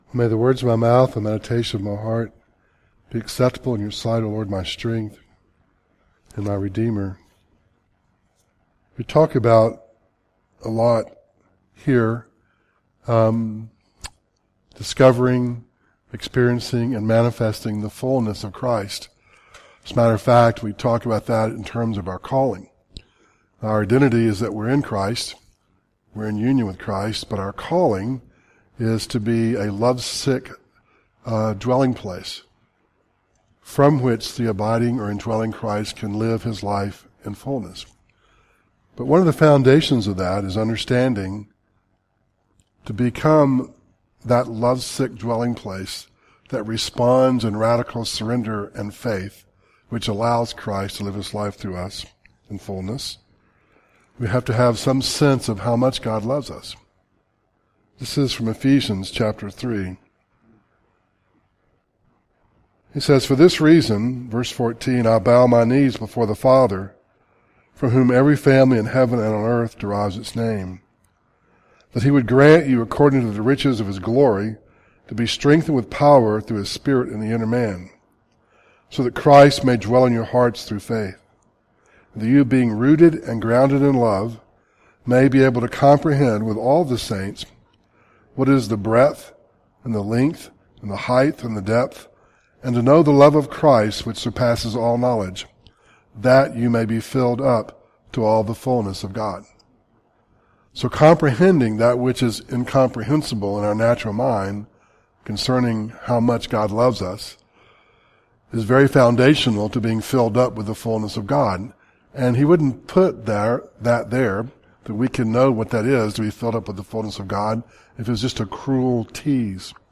Series: Audio Devotionals Ephesians 3:14-19